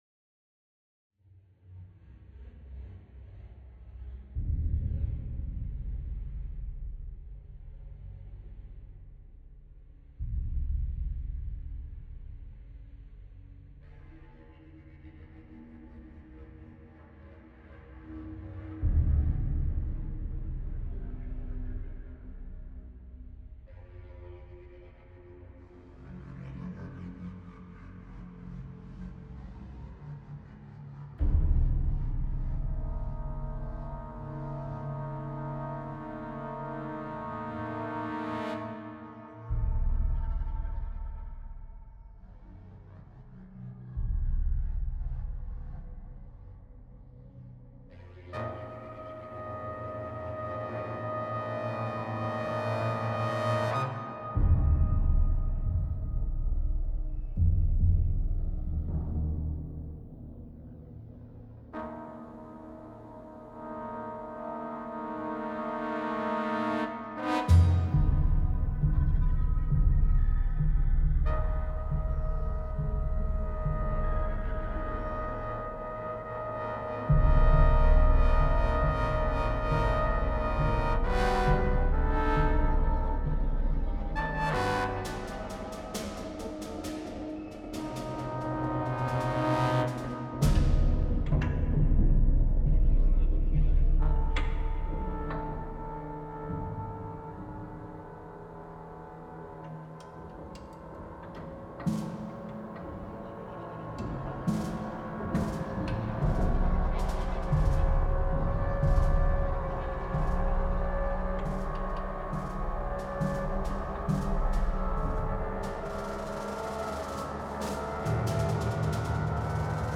Composition pour : Piano, synthétiseur, Cor Français, Trombone Basse, Bugle, Timbales, Tambour (40"x 22"), Caisse Claire, Percussions diverses et variées ainsi Composition pour : Piano, synthétiseur, Cor Français, Trombone Basse, Bugle, Timbales, Tambour (40"x 22"), Caisse Claire, Percussions diverses et variées ainsi qu'une piste sonore d'effets acoustiques.